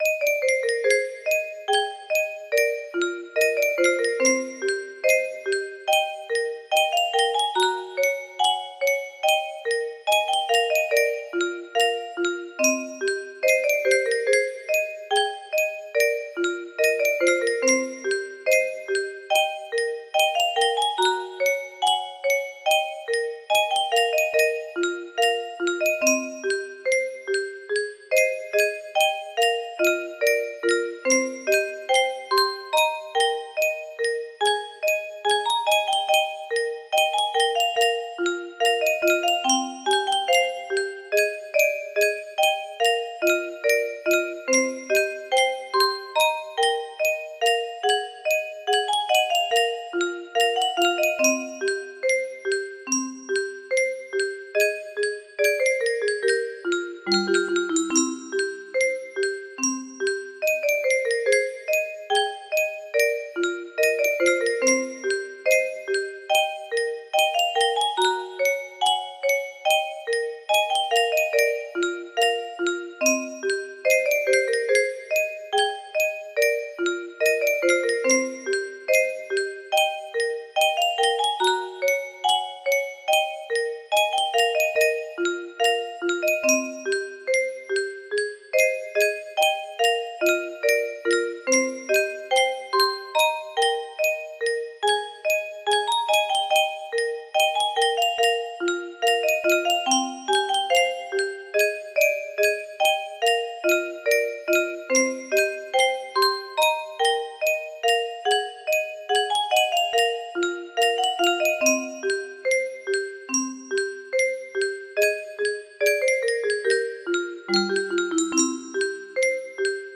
us music box melody